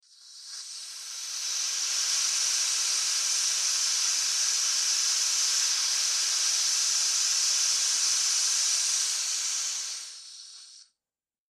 Air Hiss | Sneak On The Lot
Steady Air Hiss Rises And Dies Out, X2